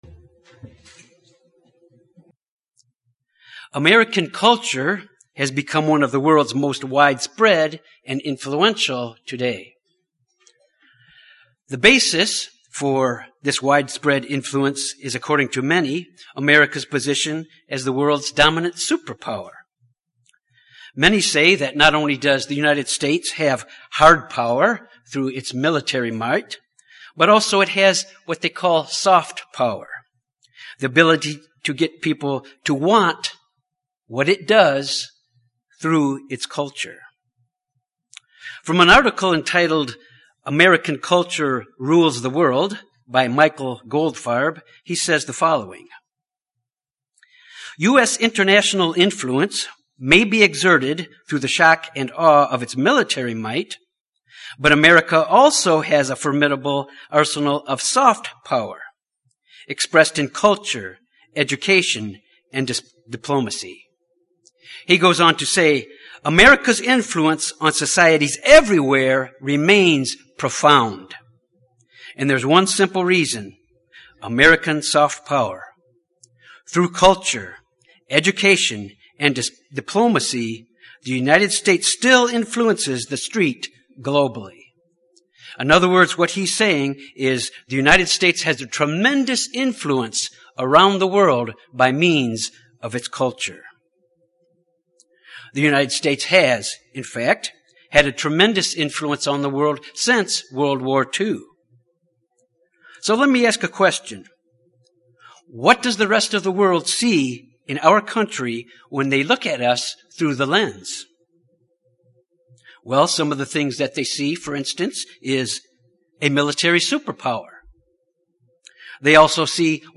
This sermon examines why the example of the nation is so important to the rest of the world and to God. The example of the nation was important in ancient times, it’s important today and it will be far more important in the future and to us as disciples, being trained to be leaders in the Kingdom.